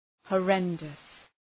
Προφορά
{hɔ:’rendəs}